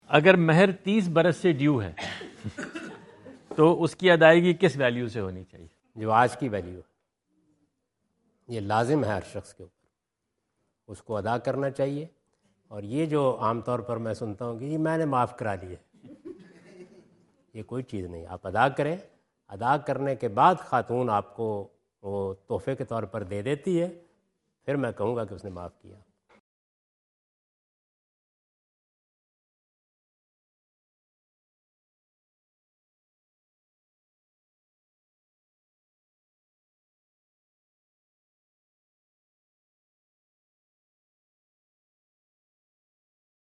Category: English Subtitled / Questions_Answers /